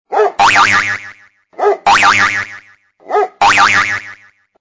» гав, пружина Размер: 21 кб